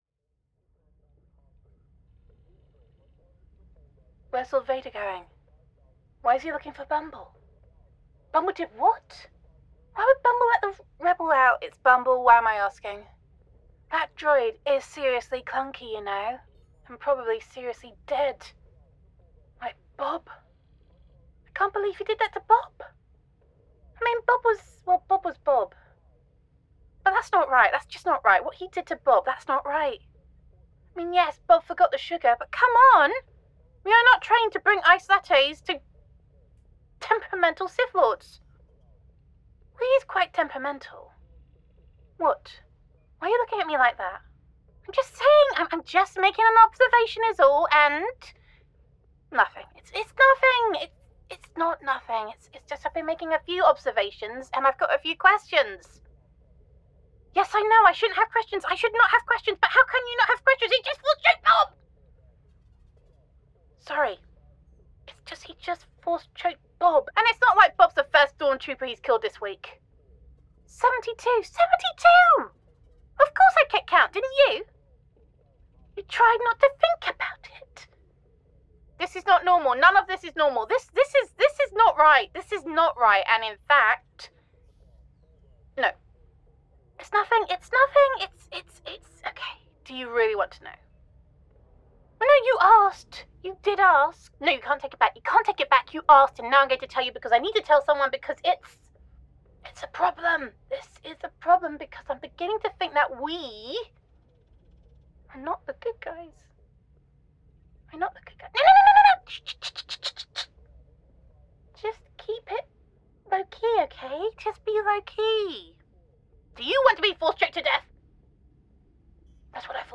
🌟🔥🎭 --- [F4A] Star Wars: Unmasking the Dark Side [Stormtrooper Roleplay][Empire Exposed][Good Guys or Bad Guys][Star Wars][Team Building Terrors][Capes Are Evil][Gender Neutral][What Happens When the Empire’s Enforcers Realise They Might Be on the Wrong Side?]